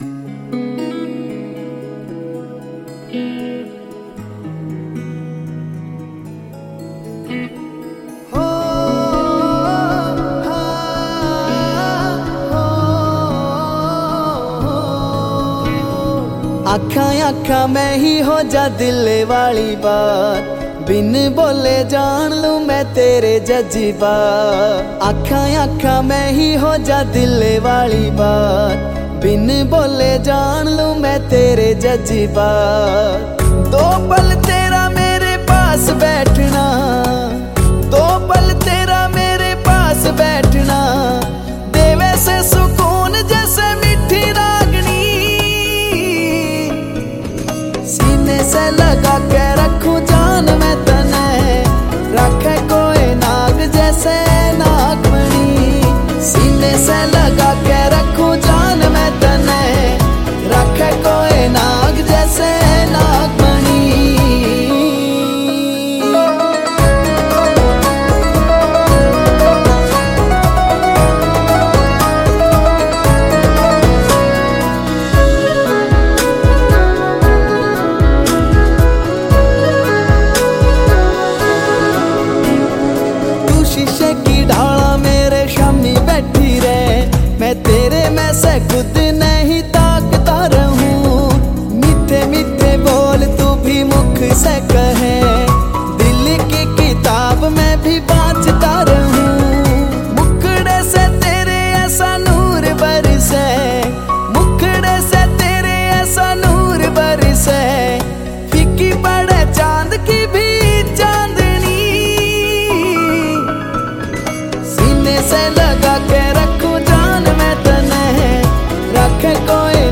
Haryanvi Mp3 Songs